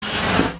inflate.mp3